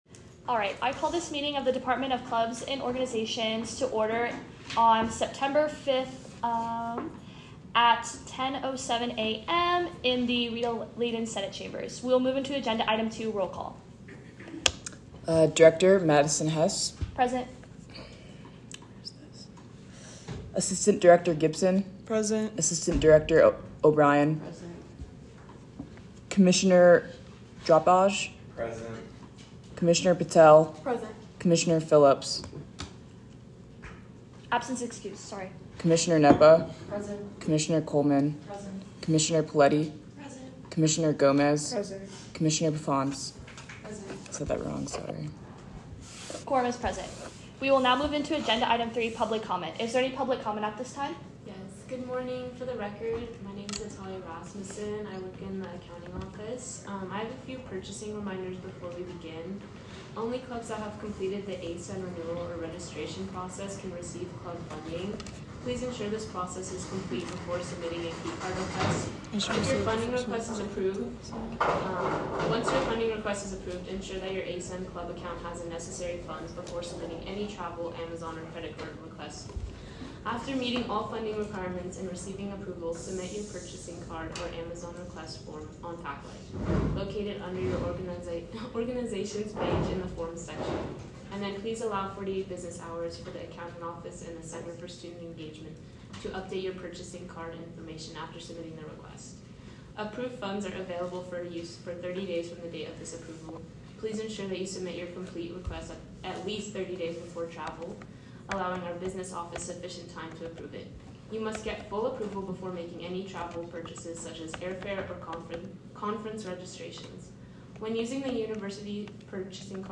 Meeting Type : Clubs and Organizations
Location : Rita Laden Senate Chambers - located on the third floor of the JCSU